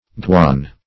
Guan \Guan\ (gw[aum]n), n. ((Zool.)